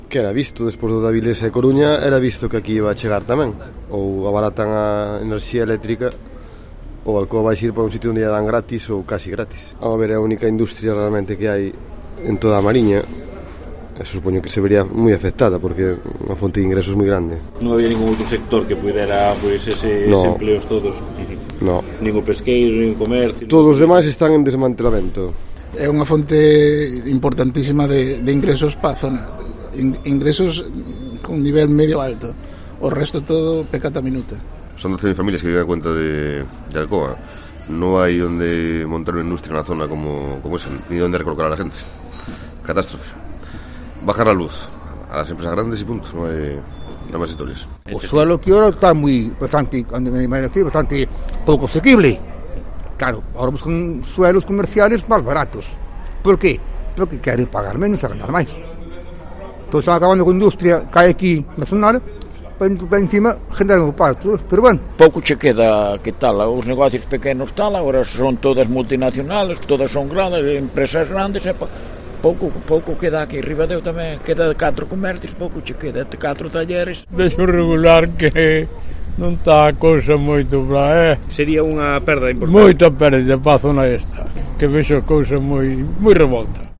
Los vecinos opinan sobre el futuro de Alcoa en el "micrófono callejero" de COPE de la Costa